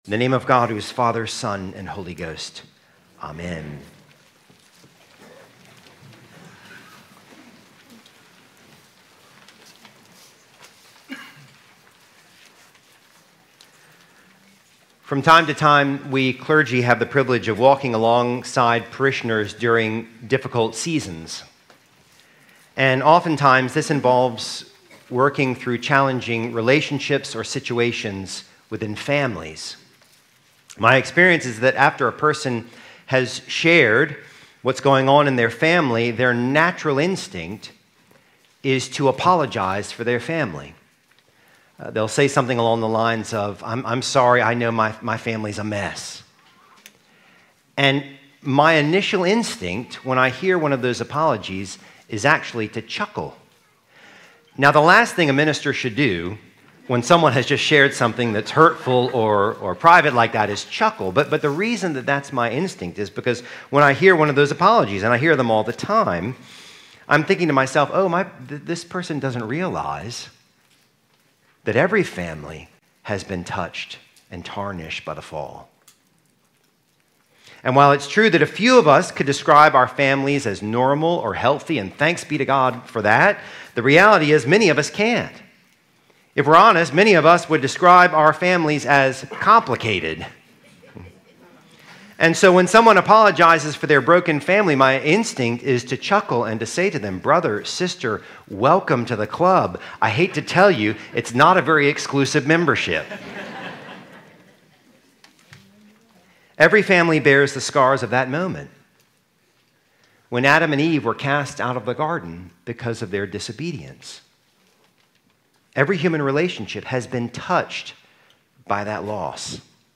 God Draws Straight With Crooked Lines | Saint Philip's Church - Charleston, SC